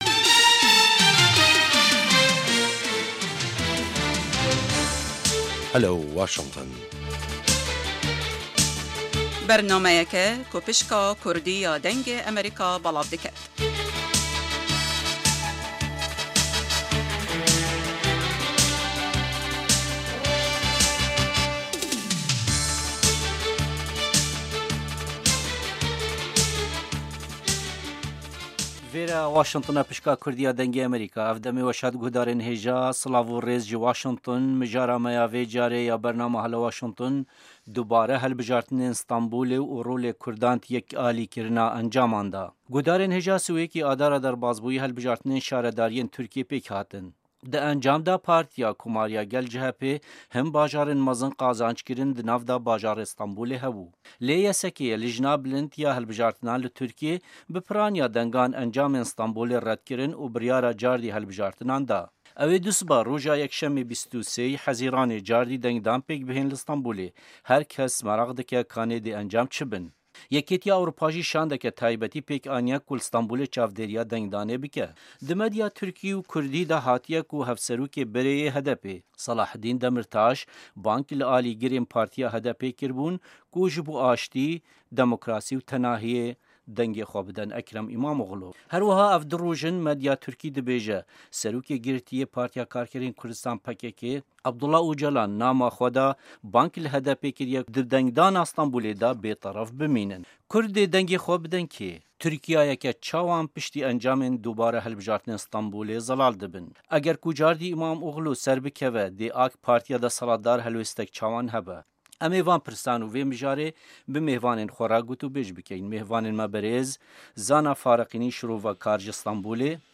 her du şirovekar